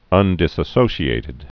(ŭndĭ-sōsē-ātĭd, -shē-)